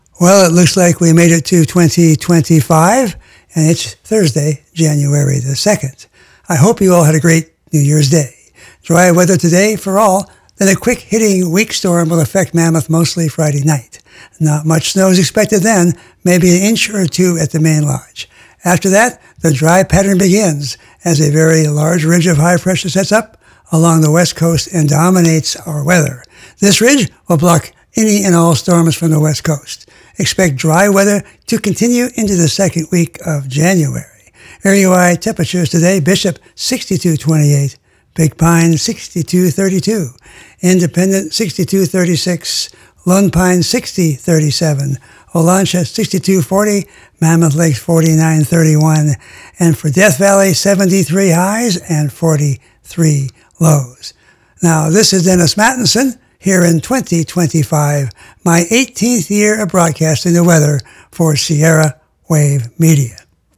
Forecast Discussion